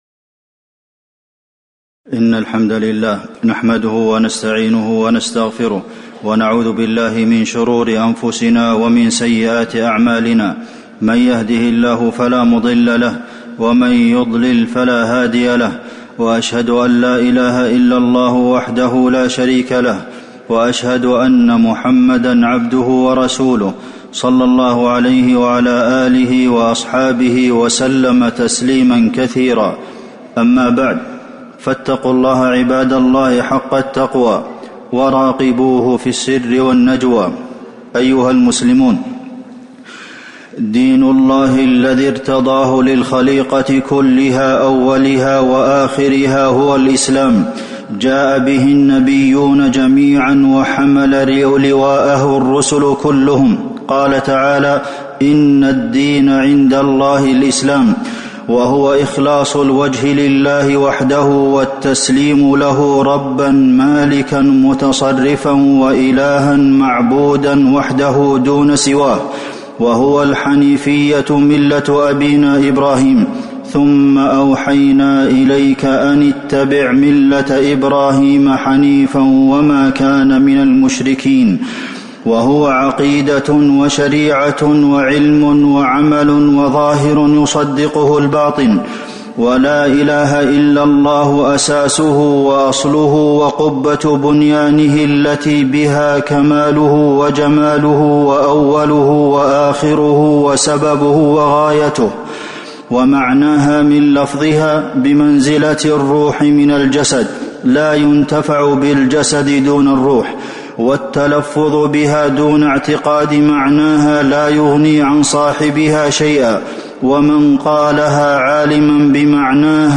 تاريخ النشر ٢٠ صفر ١٤٤٤ هـ المكان: المسجد النبوي الشيخ: فضيلة الشيخ د. عبدالمحسن بن محمد القاسم فضيلة الشيخ د. عبدالمحسن بن محمد القاسم الدعاء برهان العبودية